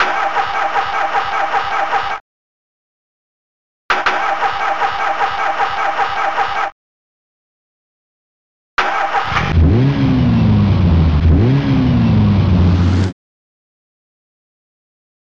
Protracker Module